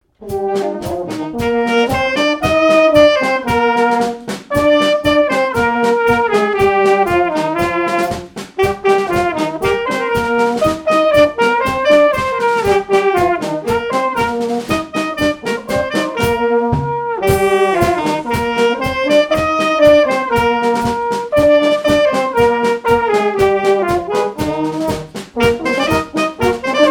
danse : quadrille : avant-deux
Répertoire pour un bal et marches nuptiales
Pièce musicale inédite